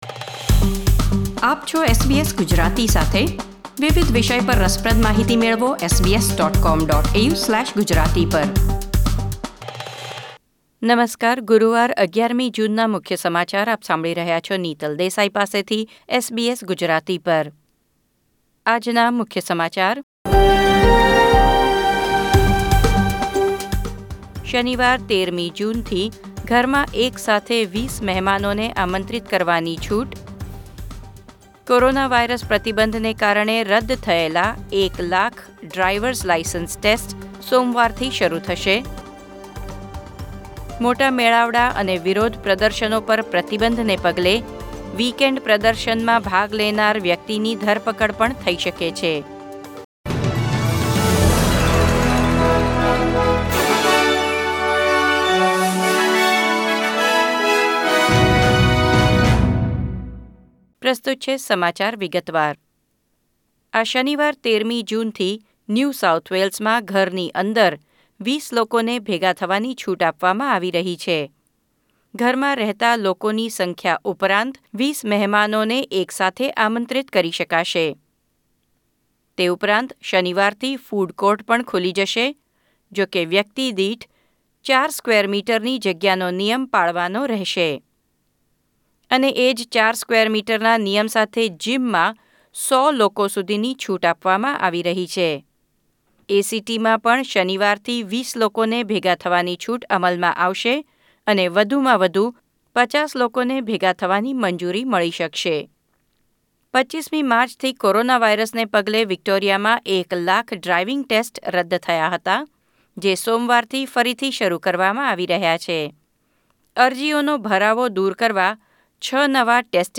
SBS Gujarati News Bulletin 11 June 2020